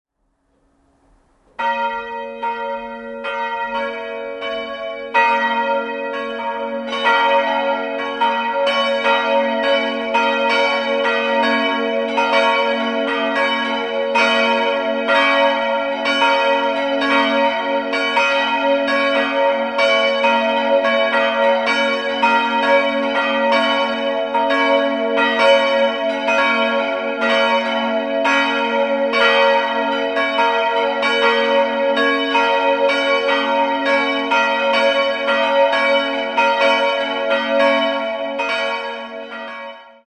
Die Glocken b' (391 kg) und des'' (217 kg) wurden 1953 von F. W. Schilling gegossen. Bei der kleinen ges'' handelt es sich möglicherweise noch um ein Werk des Ingolstädter Gießers Johann Divall aus dem Jahr 1795.